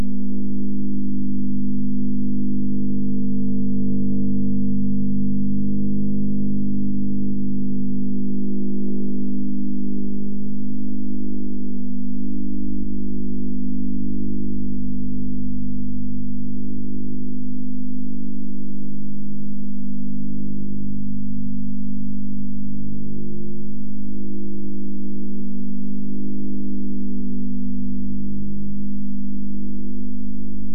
10.730Mhz on AMAmplitude Modulation Mode